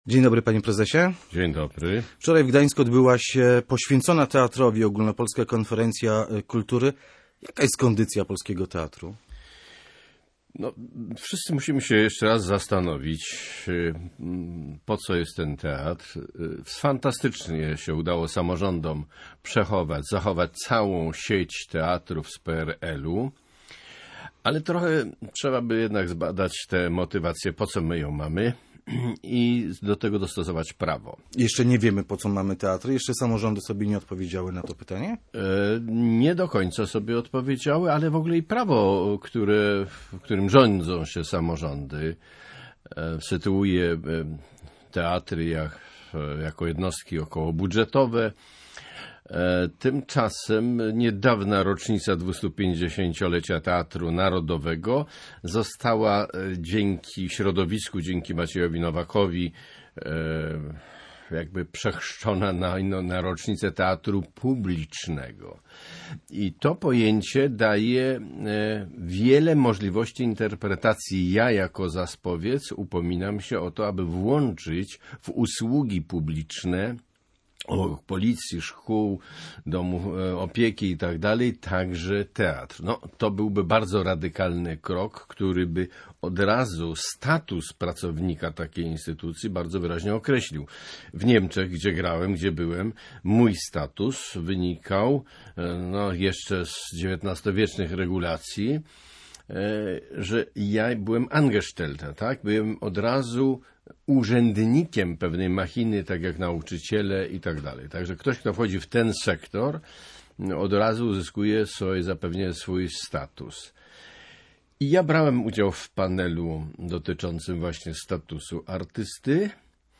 w Rozmowie Kontrolowanej rozmawiał z Olgierdem Łukaszewiczem, wybitnym aktorem, prezesem Związku Artystów Scen Polskich.